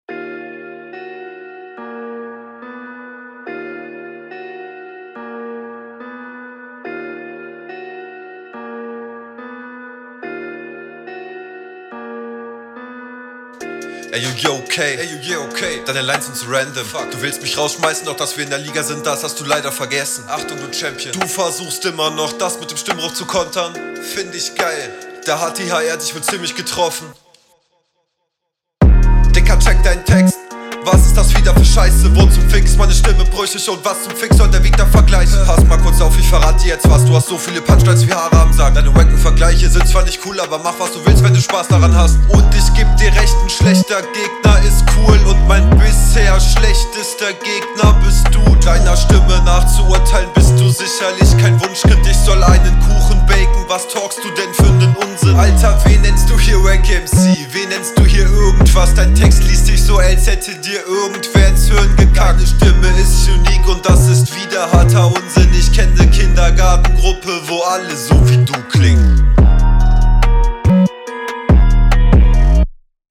Flow: ahhhh der Einstieg ist leider relativ holprig. Sobald die drums kicken, bist du wieder …